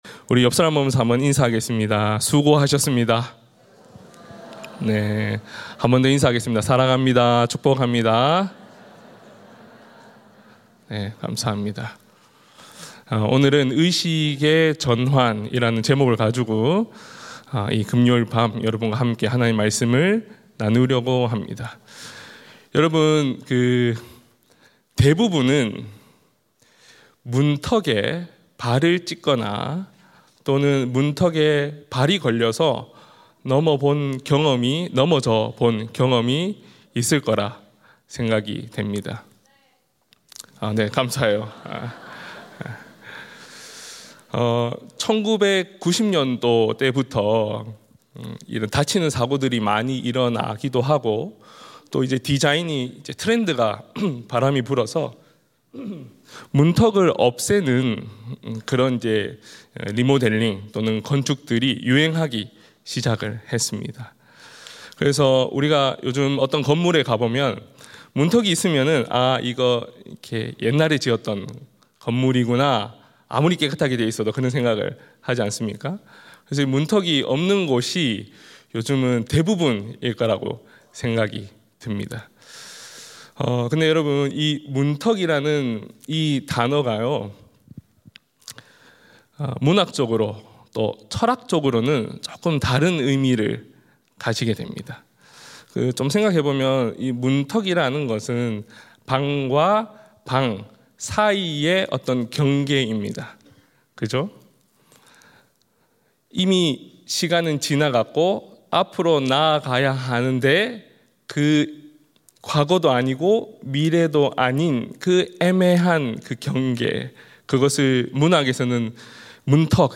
철야예배